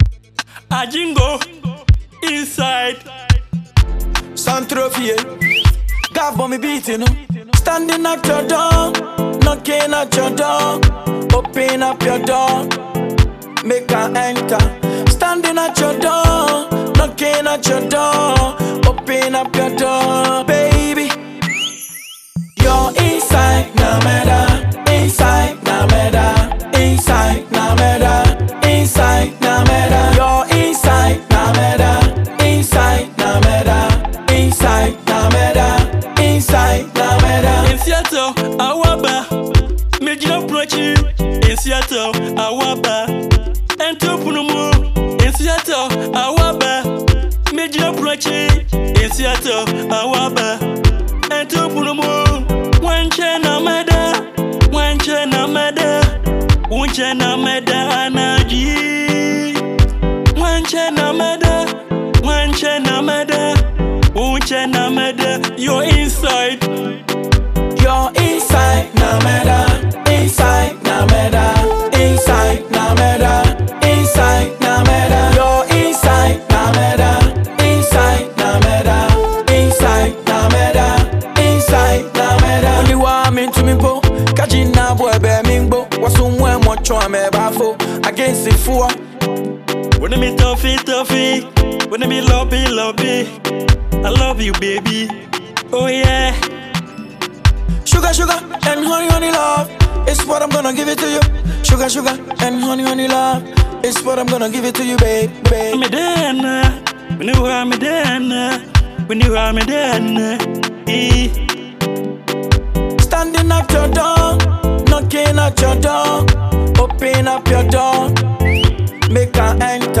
Christmas banger